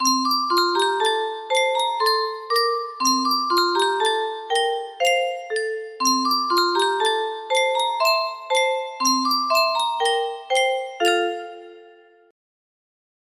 Clone of Yunsheng Caja de Musica - Tajaraste Y434 music box melody